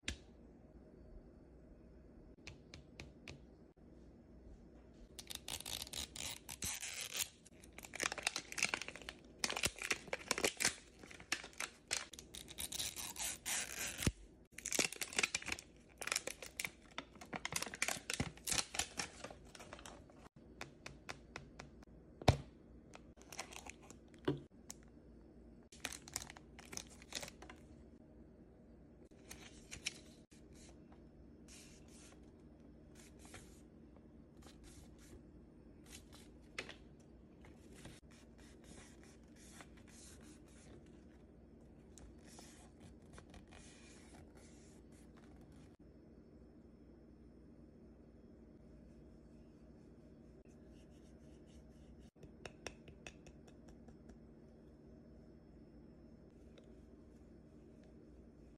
ASMR Opening Mini Brands Snackles sound effects free download